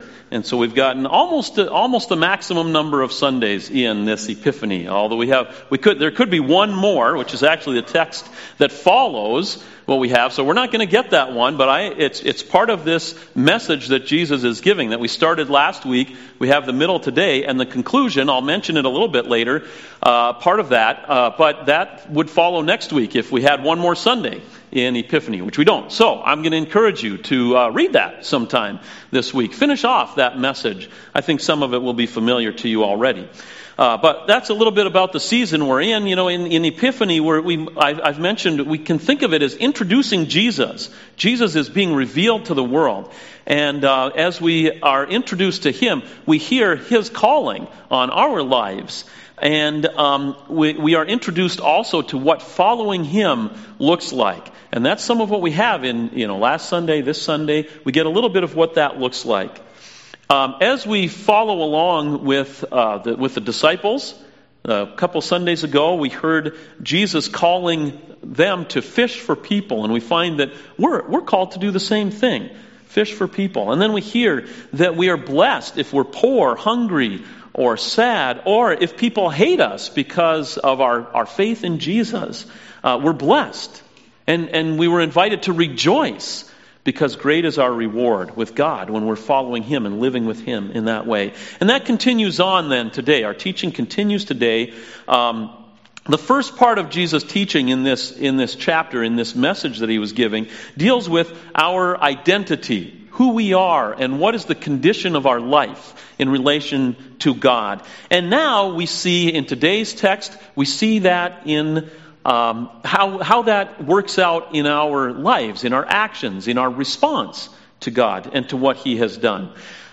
CoJ Sermons Give To Everyone (Luke 6:27-38)